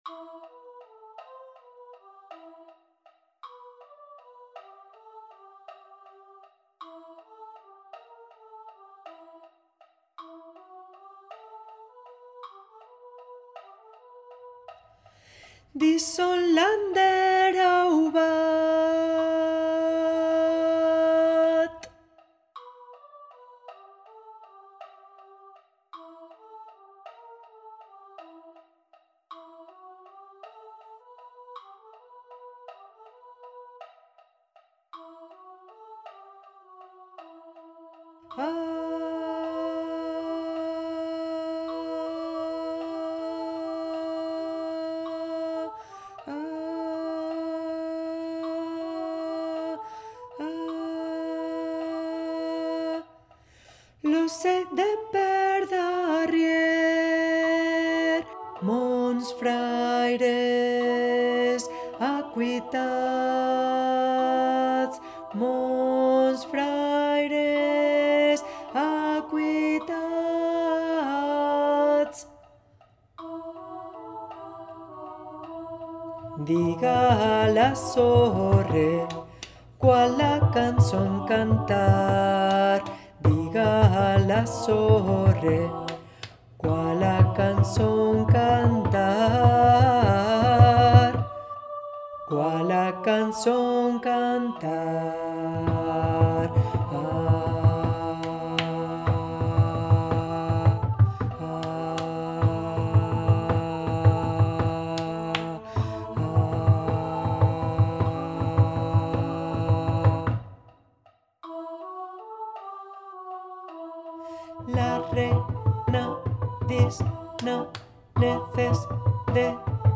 Audio grabado de ALTO 1
canción tradicional occitana
en arreglo para coro de voces iguales SMA.